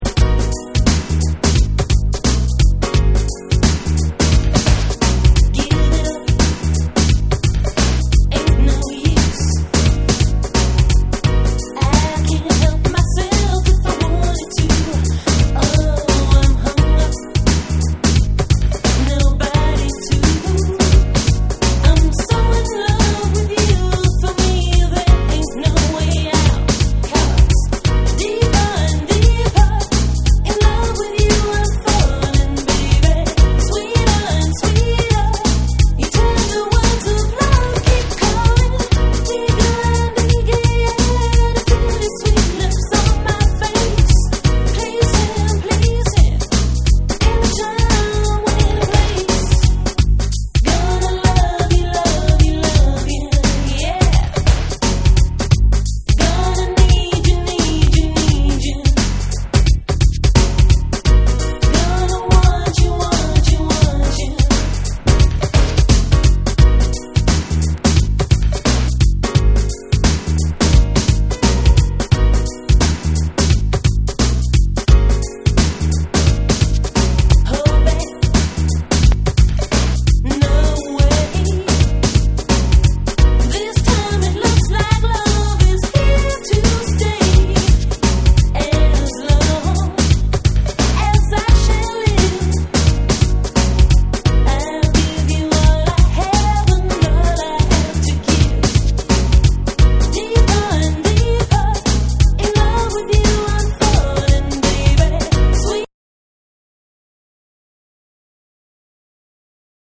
程よくレゲエの要素も含んだナイストラックです！
BREAKBEATS